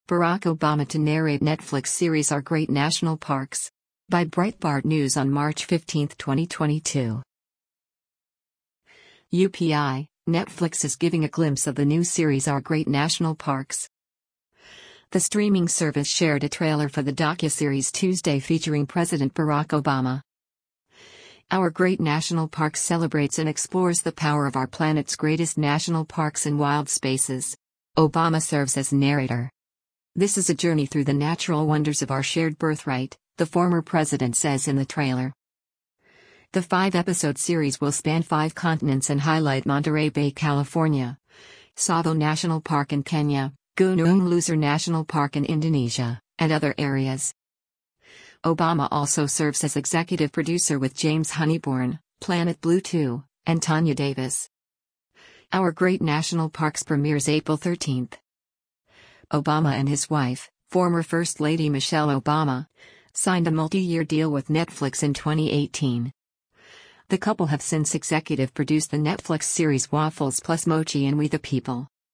The streaming service shared a trailer for the docuseries Tuesday featuring president Barack Obama.
Our Great National Parks celebrates and explores “the power of our planet’s greatest national parks and wild spaces.” Obama serves as narrator.